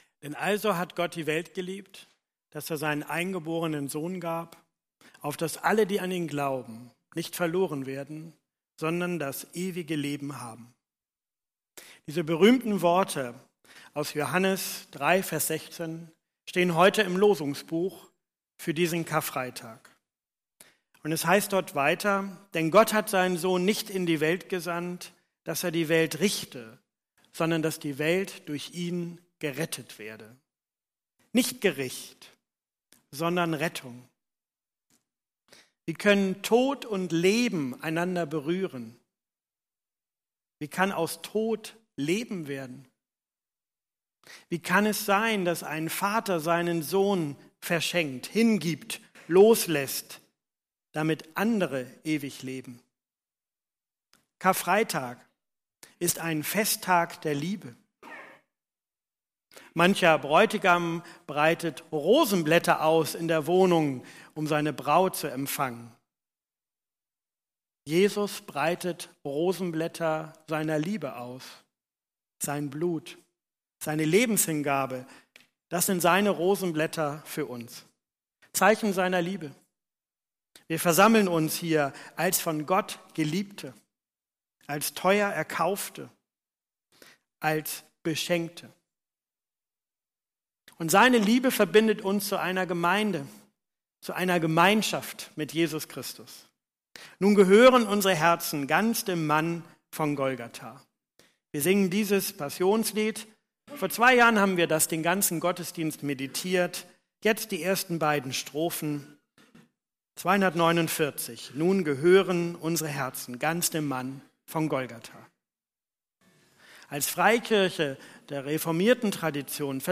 Karfreitagsmeditation „Es ist vollbracht!“